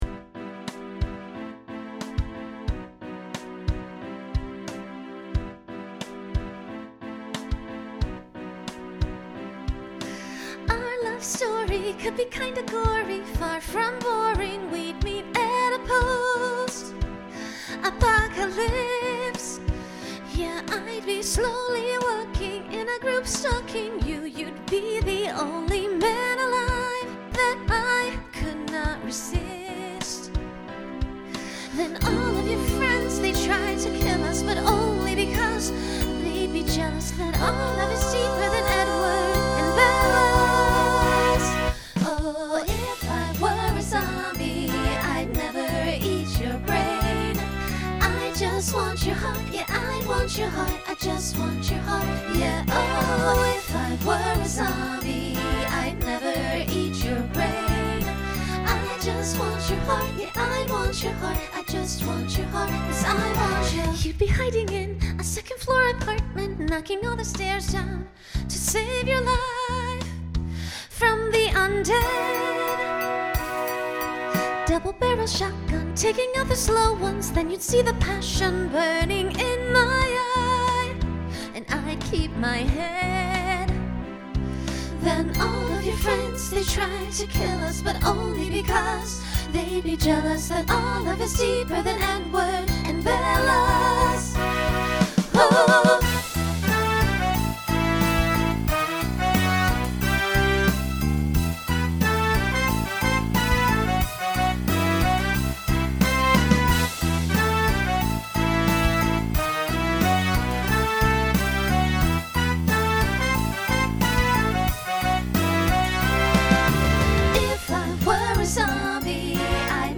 Genre Pop/Dance , Rock
Story/Theme Voicing Mixed